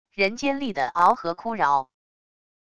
人尖厉的翱和哭饶wav音频